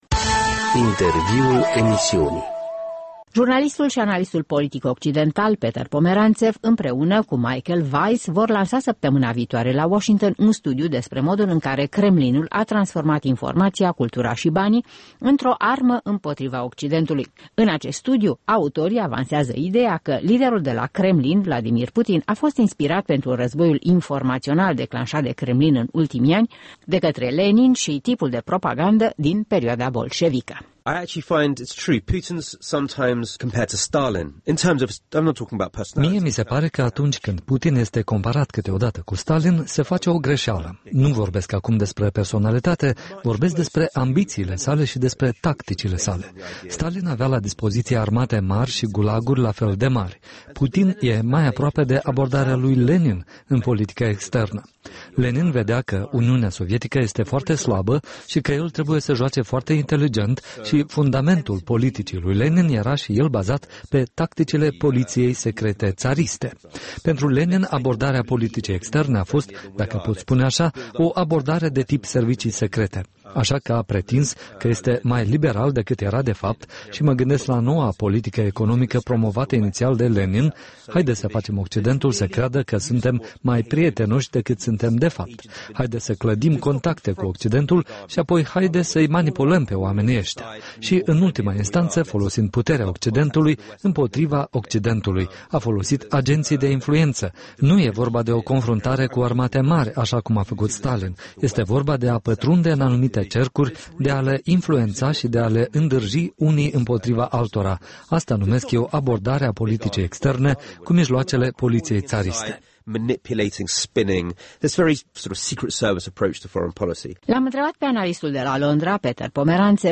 Interviuri la EL: analistul britanic Peter Pomeranțev